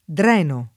DOP: Dizionario di Ortografia e Pronunzia della lingua italiana
drenare